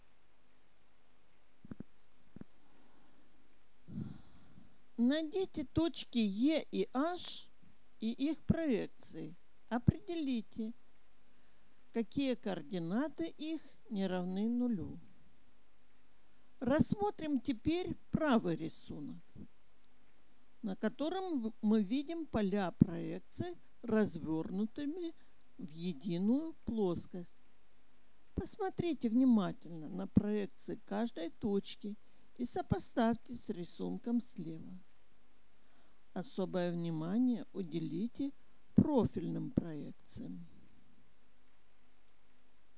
Аудио-комментарии